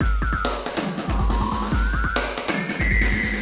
E Kit 36.wav